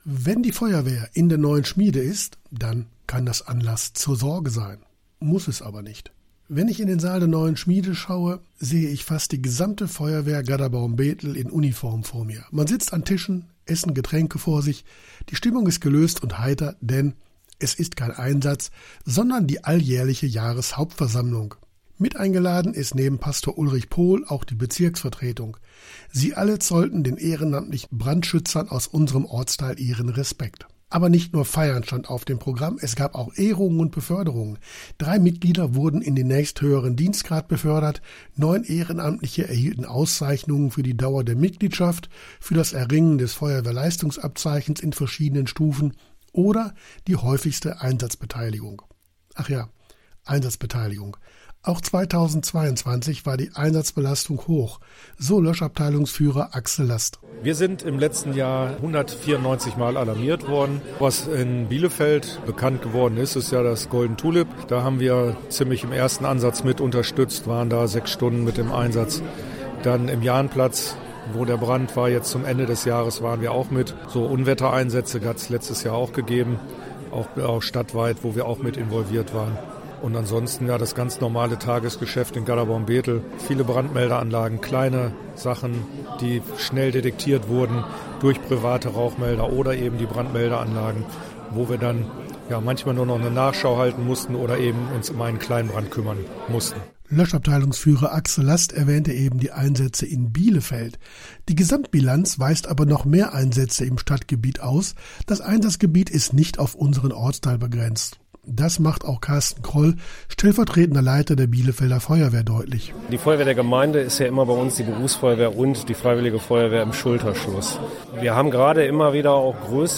Bericht über die Jahreshauptversammlung 2023 der Löschabteilung Gadderbaum/Bethel der freiwilligen Feuerwehr